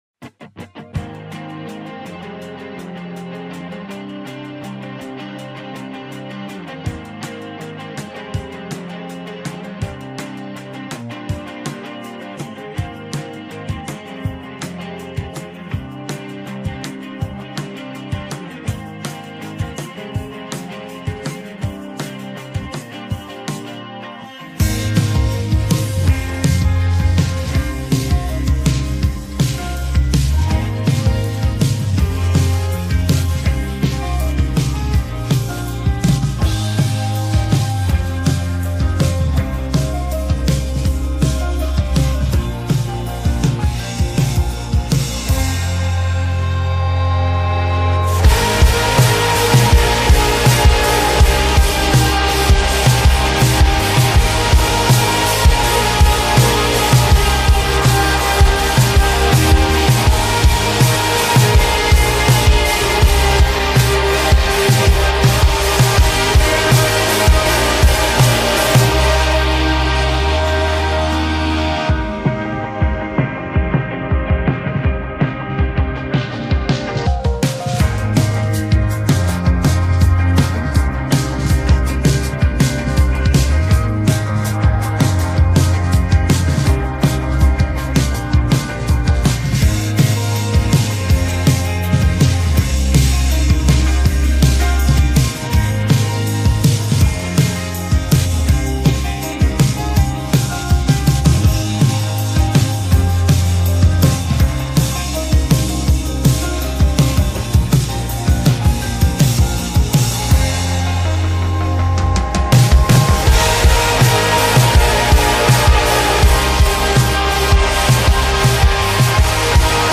Alternative Indie